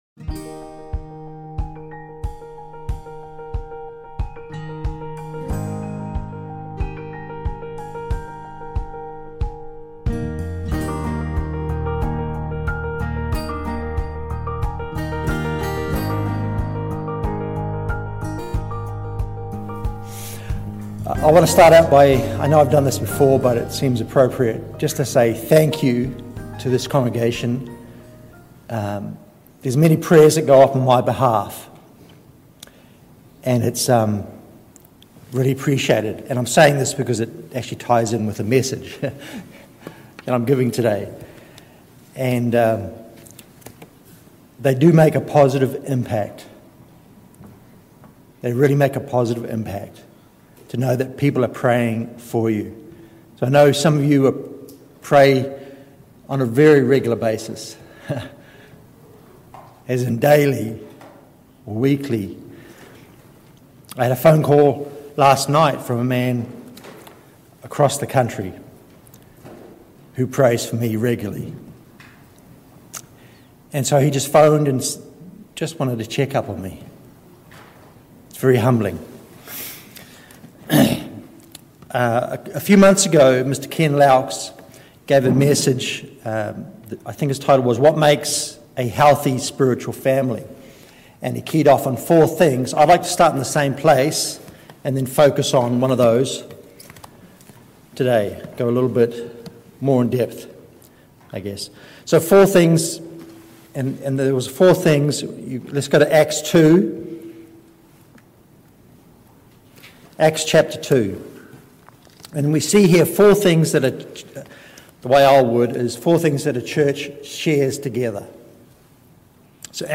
This message looks at what happens when a church prays together—how God works through delays, unexpected answers, and even unanswered requests to strengthen faith and trust. Using familiar biblical accounts, it shows why thanksgiving matters just as much as asking, and how peace can come even when circumstances don’t immediately change.